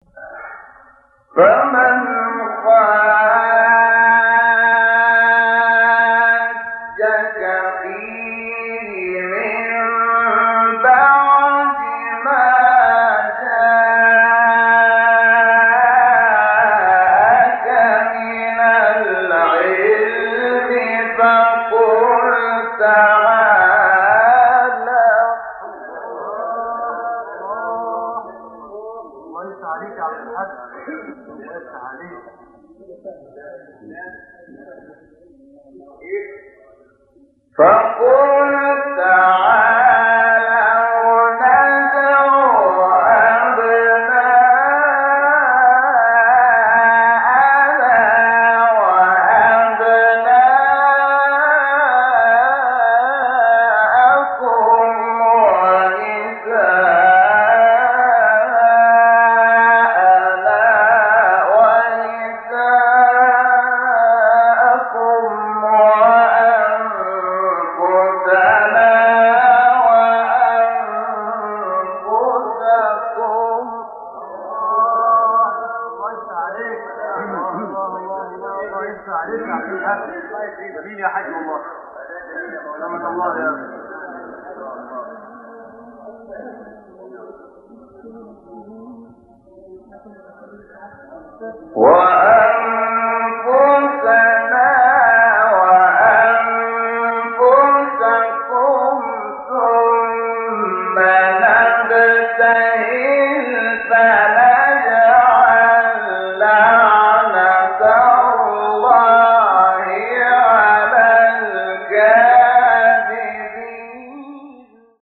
به مناسبت روز مباهله، روز حقانیت اسلام تلاوت آیه 61 سوره آل‌عمران را با صدای شحات محمد انور، قاری شهیر مصری می‌شنوید.
تلاوت آیه مباهله با صدای شحات محمد انور